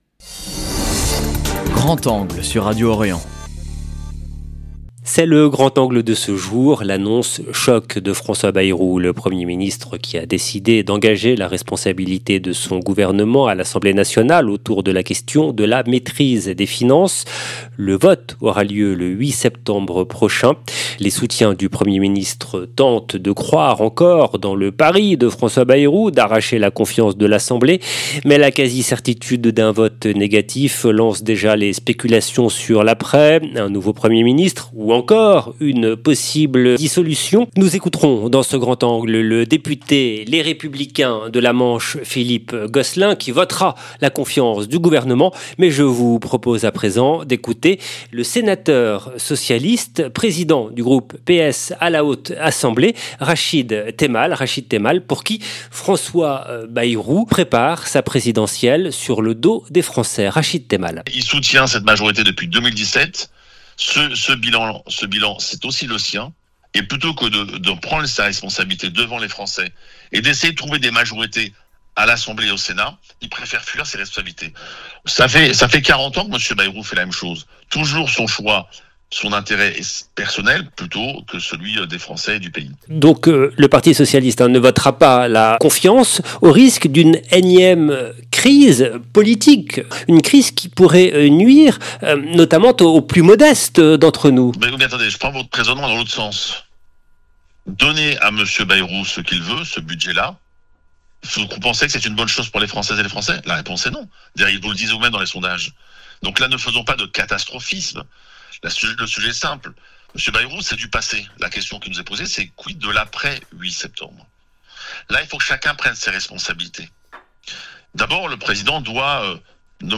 Nous écouterons dans ce grand angle le député Les Républicains de la Manche, Philippe Gosselin, qui va voter la confiance du gouvernement.